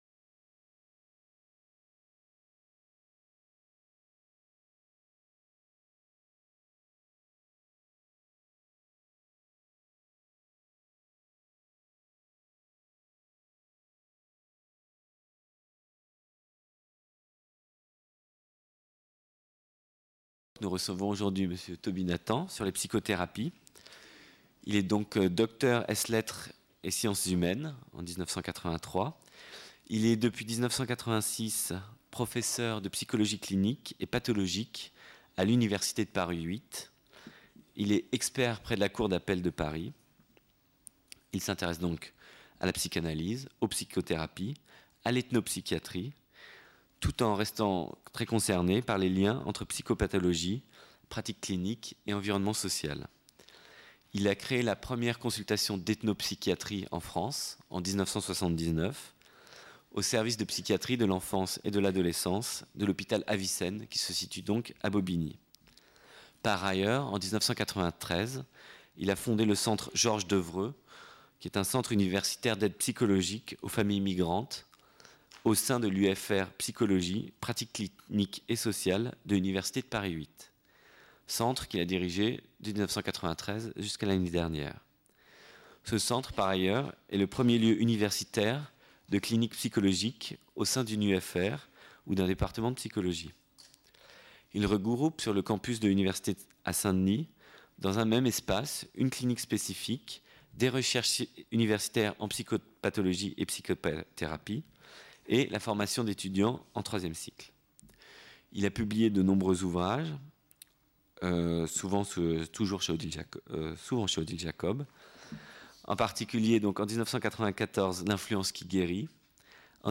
Conférence du 7 décembre 2000 par Tobie Nathan.